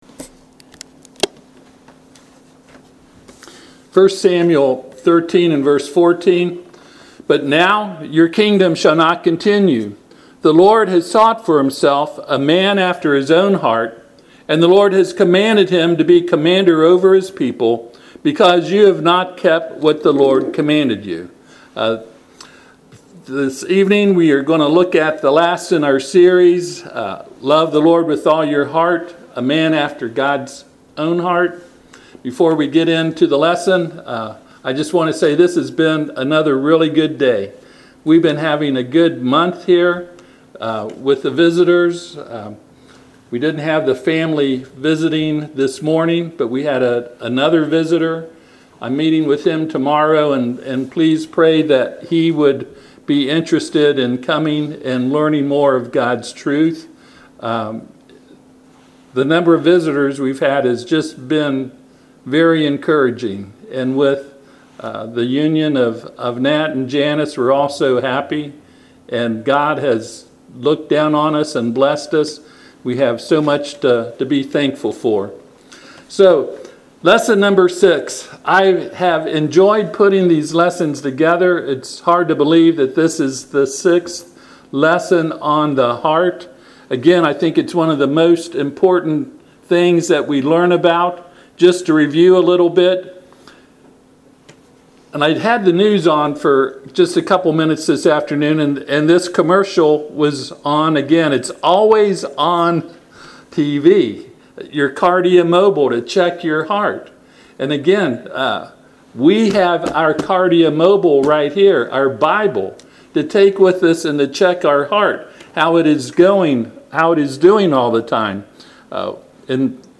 Passage: 1 Samuel 13:14 Service Type: Sunday PM